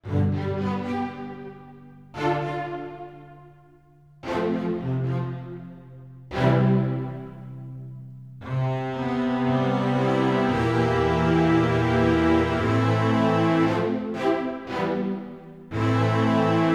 epic orchestral synth music for film and game effects